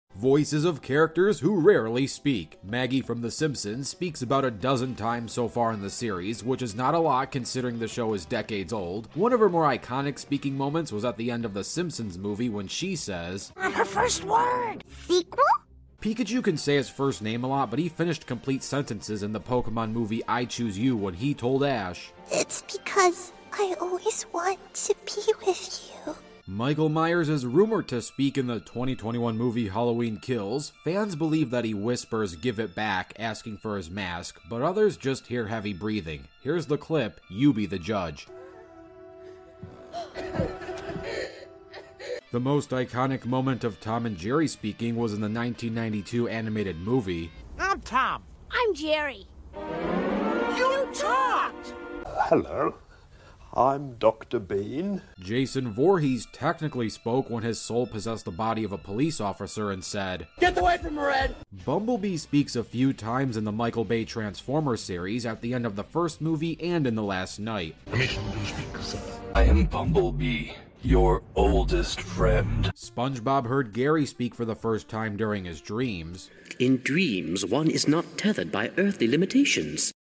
Voices of Characters who Rarely Speak! Characters such as Maggie Simpson, Bumblebee, and more!